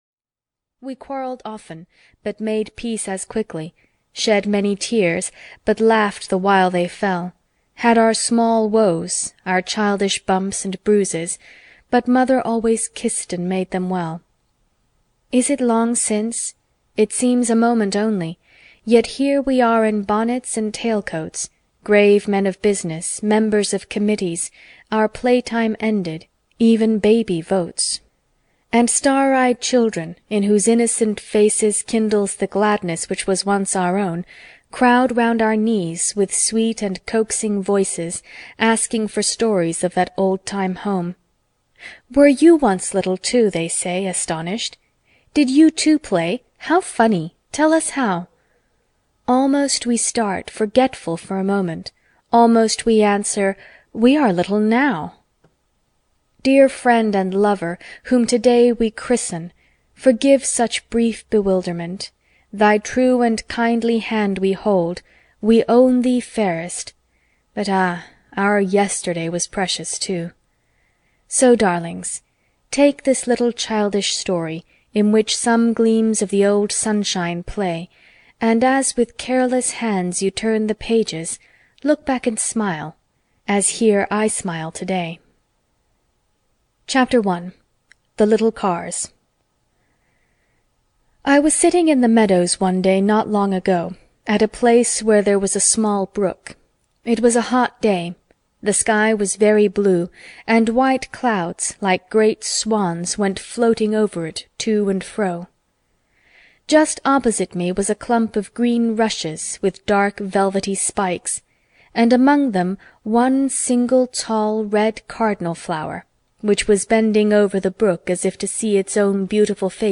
What Katy Did (EN) audiokniha
Ukázka z knihy